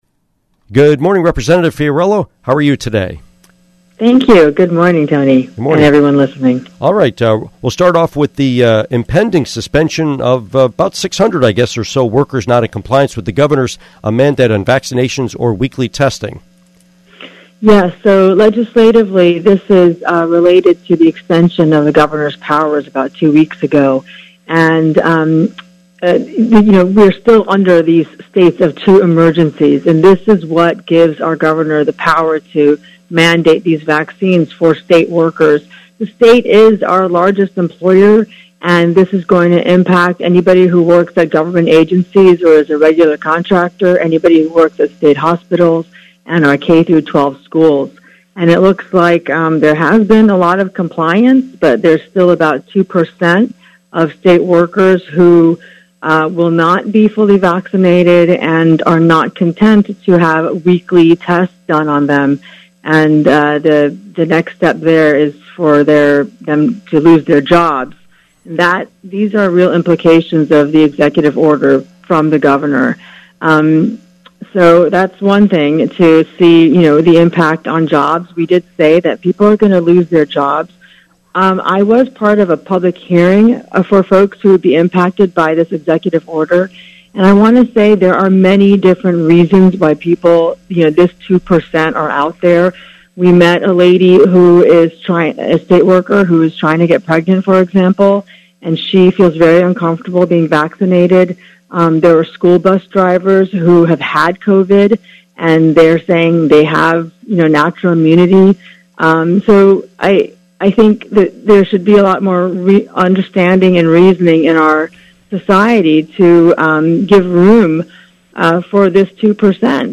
Interview with State Representative Kimberly Fiorello